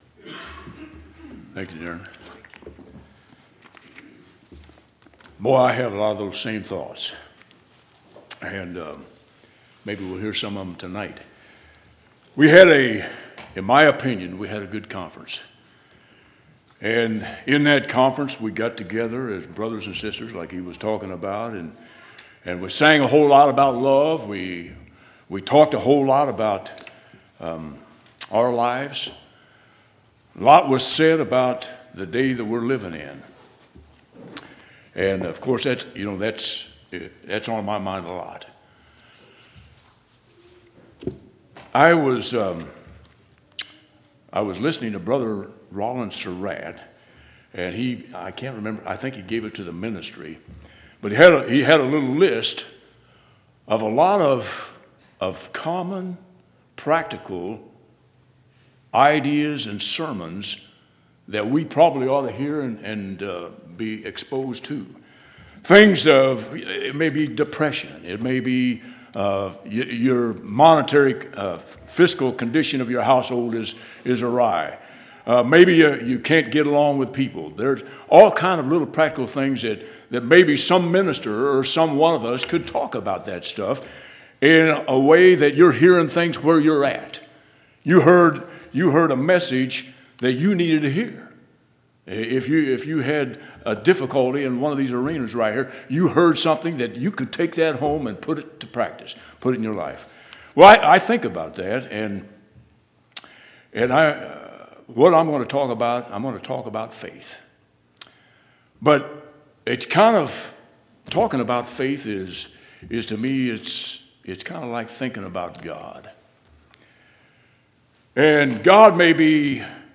4/19/2015 Location: Temple Lot Local Event